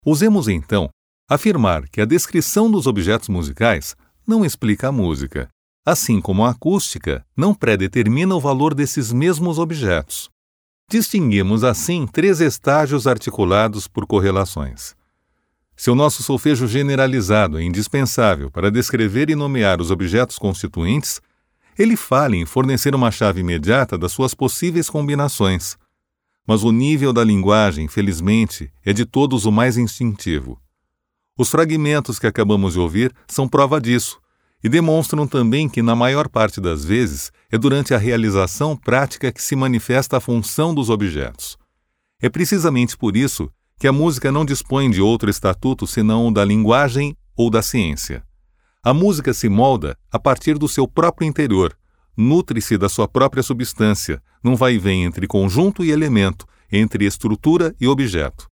It is important to highlight that my natural accent is the standard Brazilian broadcast accent.
Audiobooks
Audiobook – By Pierre Schaeffer